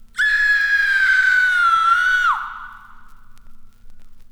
• scream scary - female - horror.wav
scream_scary_-_female_-_horror_HsV.wav